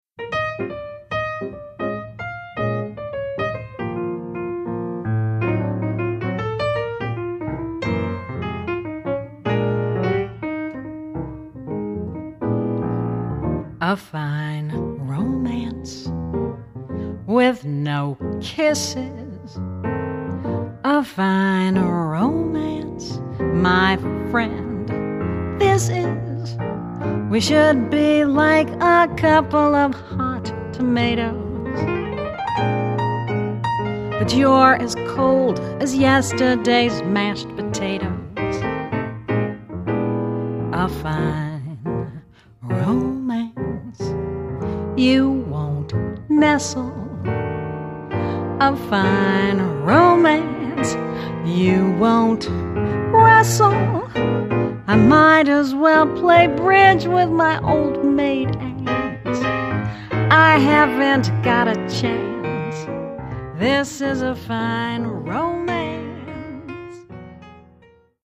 vocals
piano